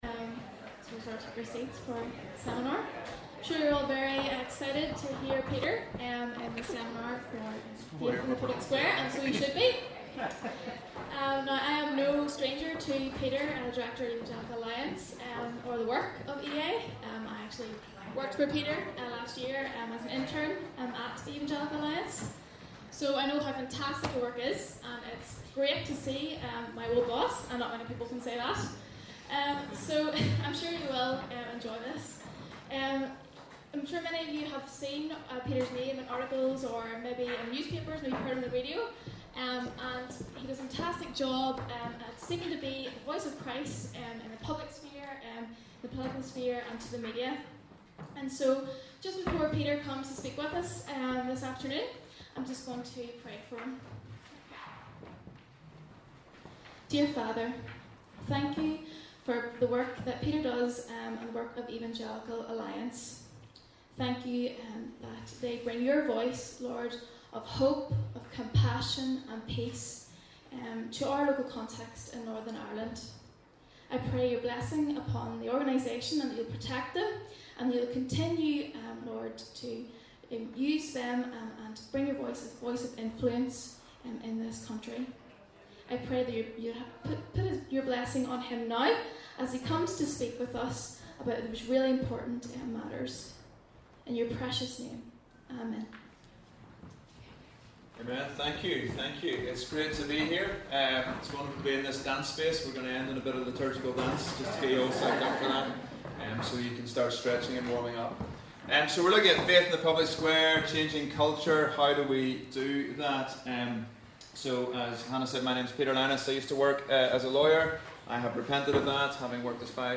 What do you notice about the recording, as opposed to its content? This recording is taken from the Transform Gathering which took place in the Cathedral Quarter, Belfast from 1st-2nd April 2016.